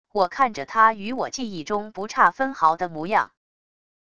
我看着他与我记忆中不差分毫的模样wav音频生成系统WAV Audio Player